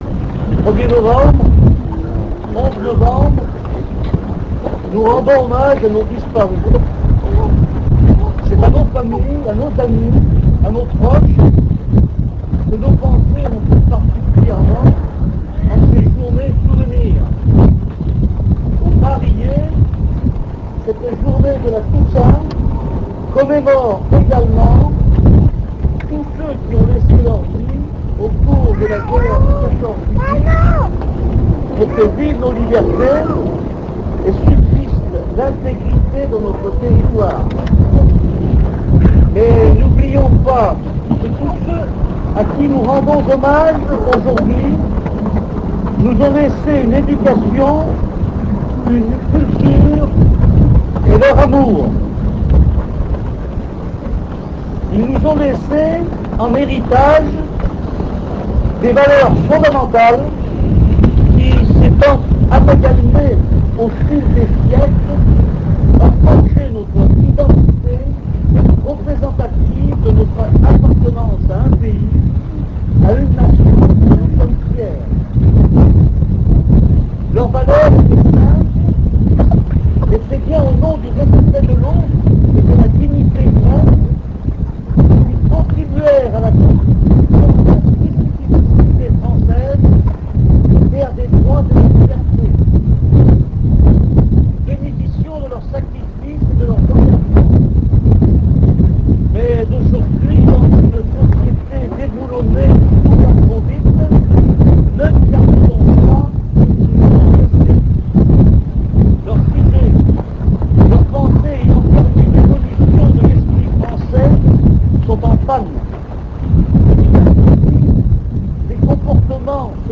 Cérémonie du 11 Novembre
Simple et solennelle cette cérémonie a encore une fois réunie beaucoup de monde, le cimetière accueillant le monument aux morts étant juste assez grand. Après un petit discours que nous vous proposons en exclusivité , tout le monde c'est retrouvé à la salle des fêtes pour un non moins traditionnel "pot de l'amitié" (pardonnez la qualité acoustique dûe en partie à un vent soutenu et a un taux de compression élevé permettant la diffusion sur la "toile").